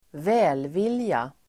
Uttal: [²v'ä:lvil:ja]